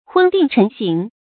昏定晨省 hūn dìng chén xǐng
昏定晨省发音
成语注音 ㄏㄨㄣ ㄉㄧㄥˋ ㄔㄣˊ ㄒㄧㄥˇ